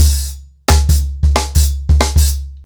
TrackBack-90BPM.11.wav